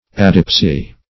Search Result for " adipsy" : The Collaborative International Dictionary of English v.0.48: Adipsia \A*dip"si*a\, Adipsy \A*dip"sy\([a^]d"[i^]p*s[y^]), n. [Gr.